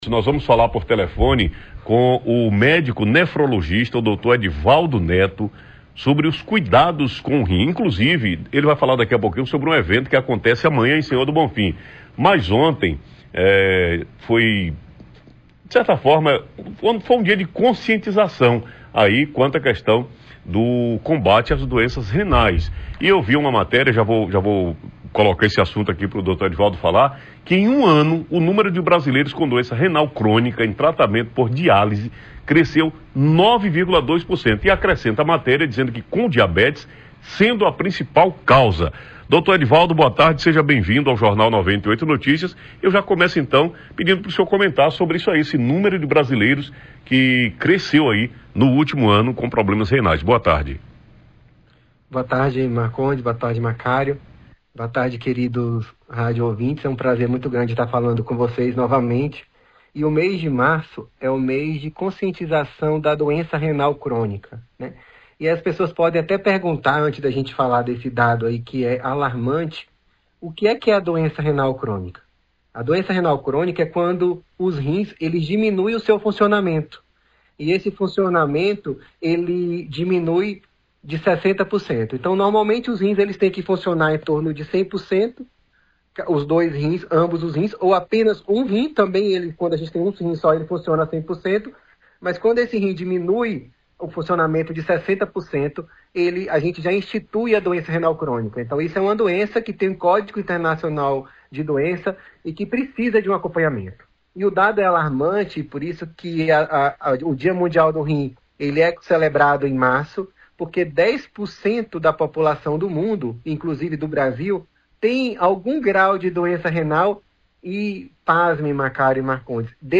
Nefrologista fala sobre evento que acontece nesse sábado em Senhor do Bonfim sobre as doenças renais
nefrologista.mp3